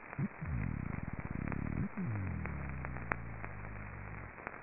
Sounds Made by Epinephelus guttatus
Type of sound produced courtship associated sounds and territorial displays of males
Sound production organ swim bladder
Sound mechanism vibration by contraction of associated muscles (probably similar as in Epinephelusstriatus)
Behavioural context courtship displays between males and females and territorial displays by males recorded in situ during the spawning aggregation in western Puerto Rico